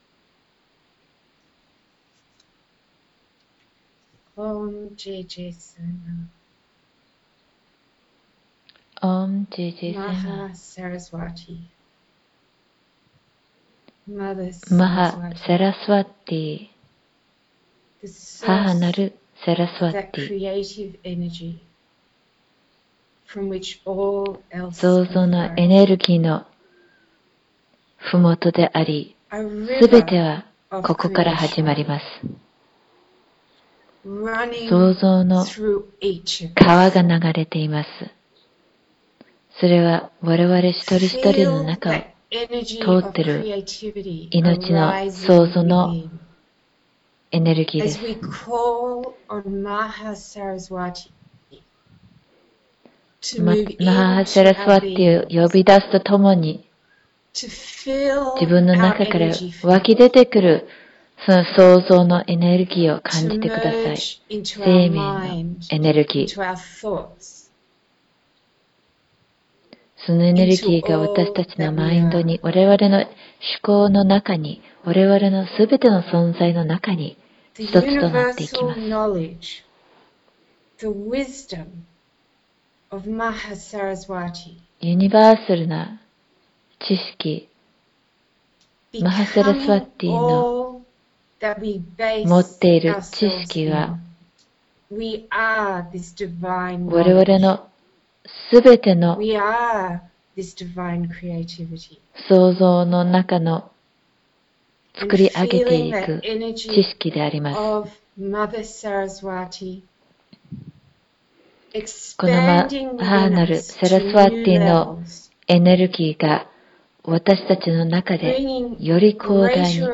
サイマーのブラマチャーリ（僧尼）が録音した誘導瞑想を以下からお聴きになるか、 こちら からダウンロードしていただけます。
サラスヴァティの瞑想.mp3